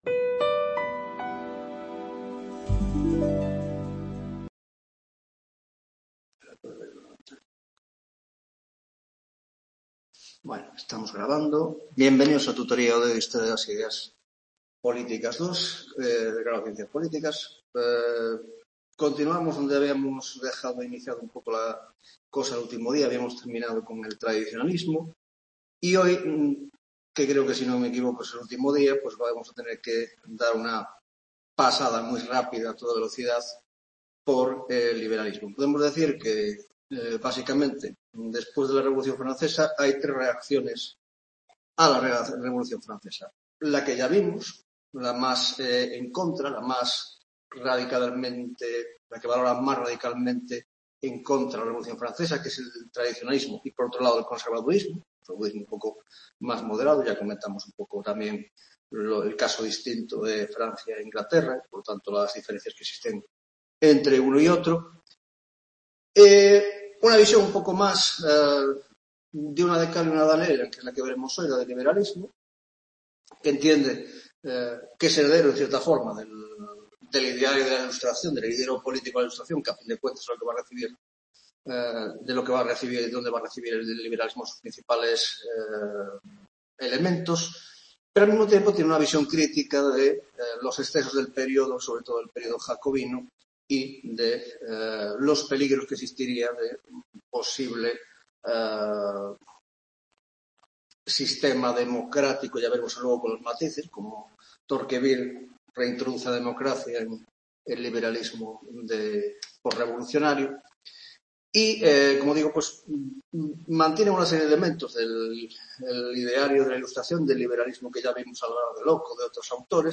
Tutoría de Historia de las Ideas Políticas 2 (Grado de Ciencias Políticas)